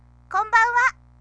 綾はしゃべったりもします。